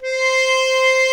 C5 ACCORDI-R.wav